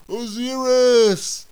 warrior_die3.wav